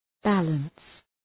Προφορά
{‘bæləns}